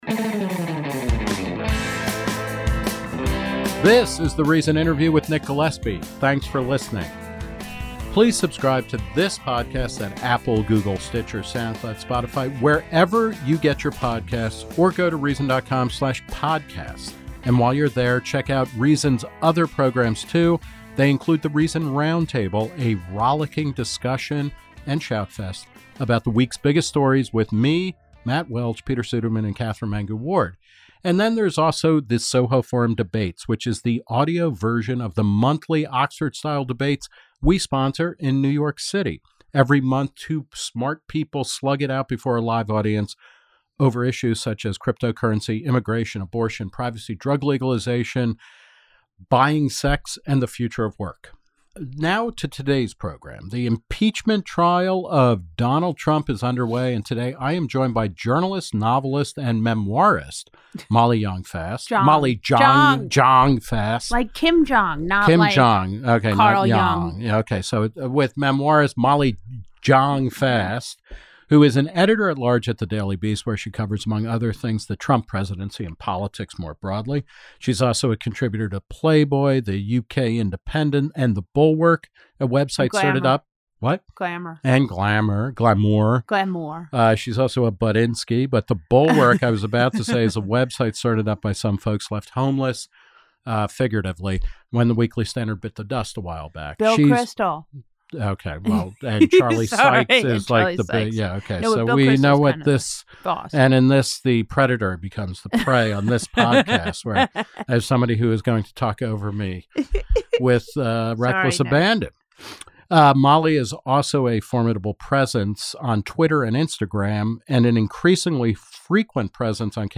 Nick Gillespie interviews novelist and Daily Beast Editor at Large Molly Jong-Fast about Donald Trump's impeachment, Democratic presidential candidates, and why she thinks Twitter is about the best thing ever.